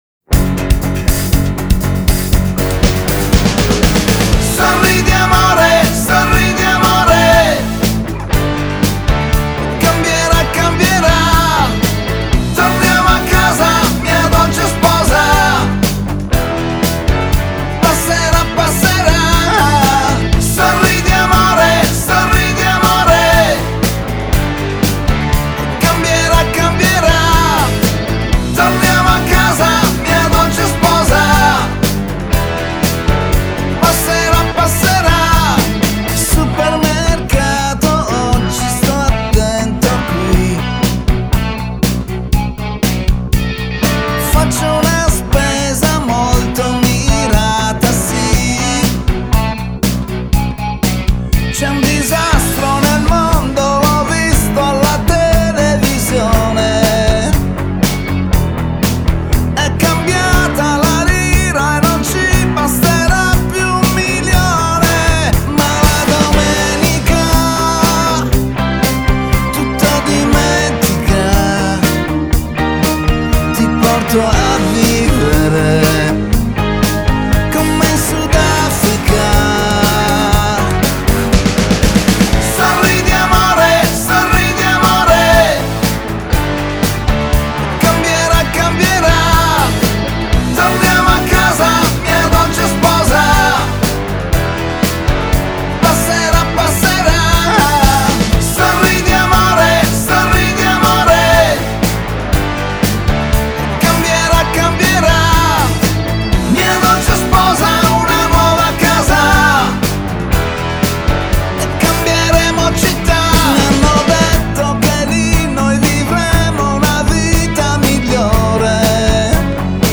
Genre: Pop, Pop Rock